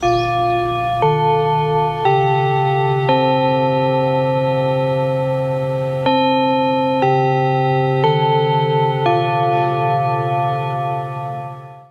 Японски звънец за клас